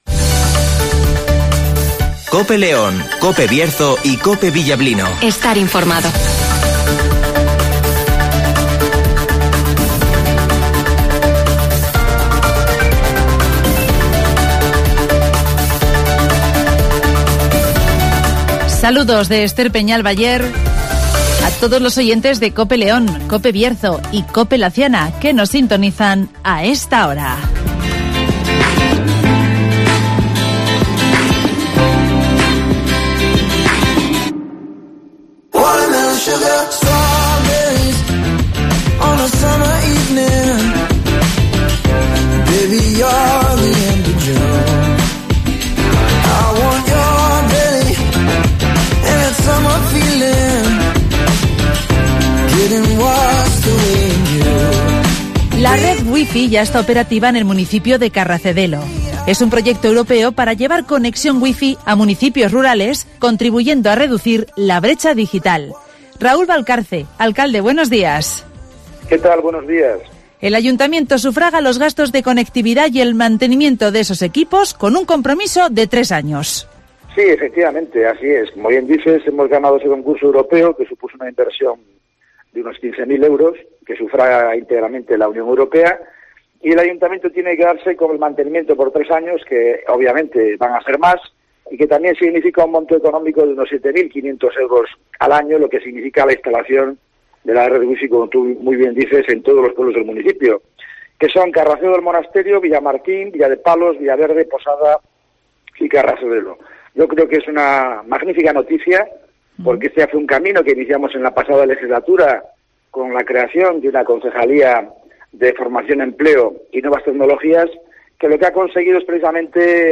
La red WiFi4EU ya está operativa en el municipio de Carracedelo (Entrevista a Raúl Valcarce, alcalde del municipio)